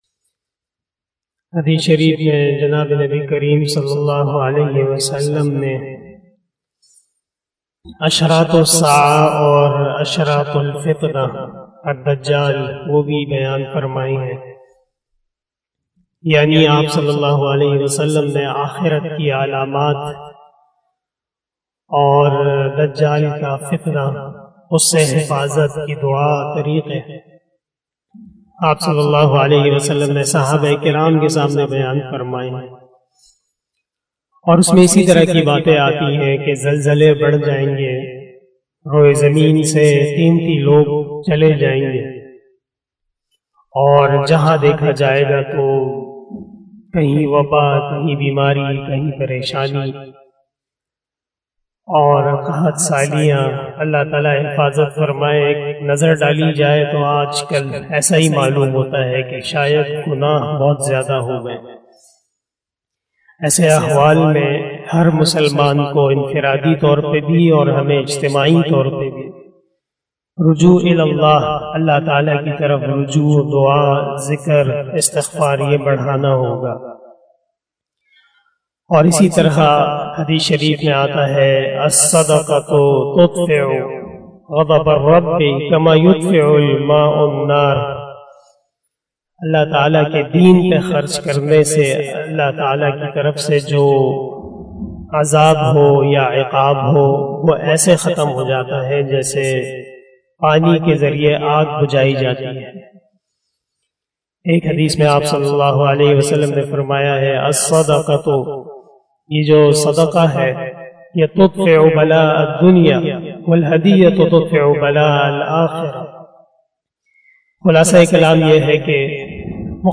064 After Asar Namaz Bayan 11 September 2021 (03 rbiul awwal 1443HJ) Sunday